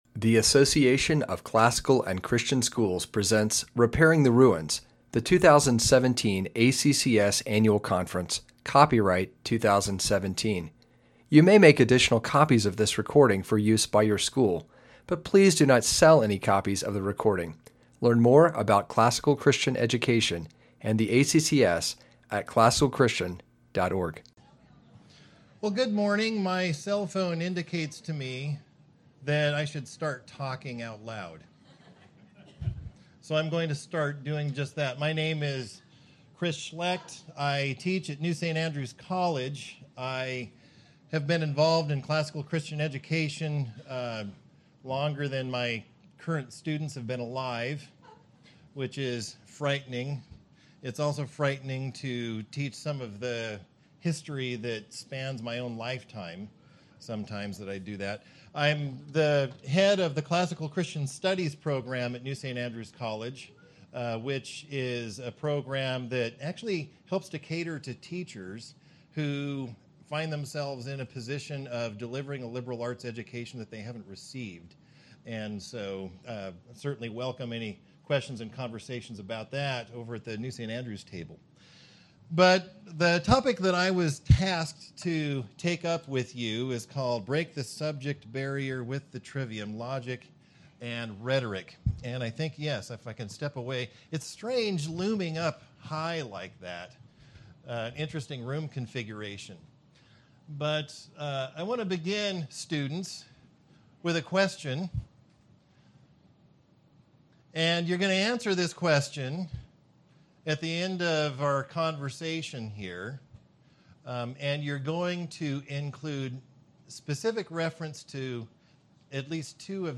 2017 Foundations Talk | 2:55:14 | 7-12, History, Latin, Greek & Language, Literature
The Association of Classical & Christian Schools presents Repairing the Ruins, the ACCS annual conference, copyright ACCS.